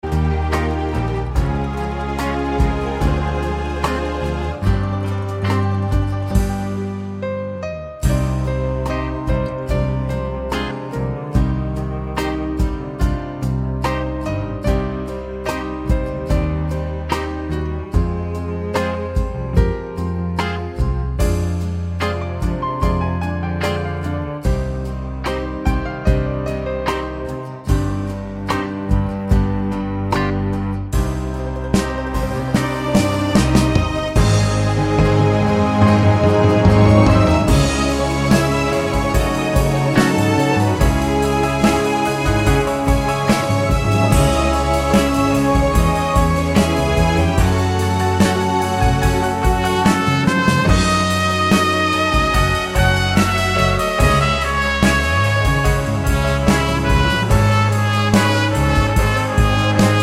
no Backing Vocals Crooners 3:06 Buy £1.50